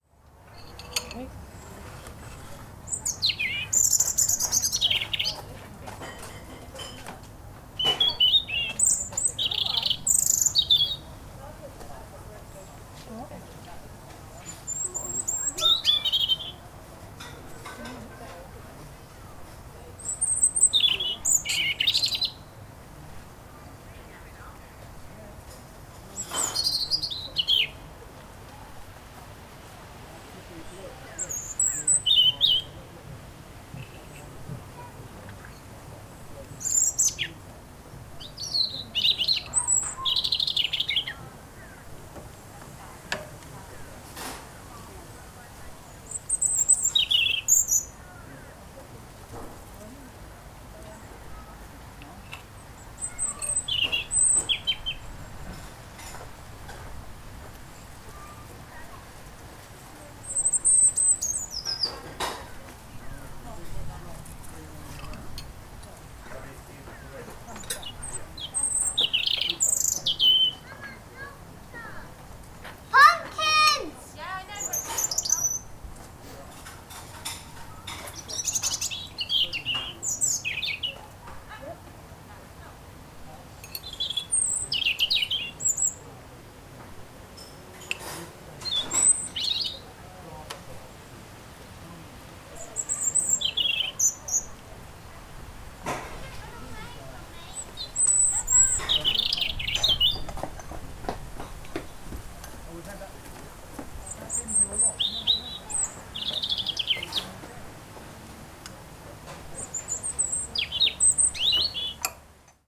A Robin Singing At The Summer Cafe
Category 🌿 Nature
Ambience Ambient ASMR Atmosphere Atmospheric Audio Background Calm sound effect free sound royalty free Nature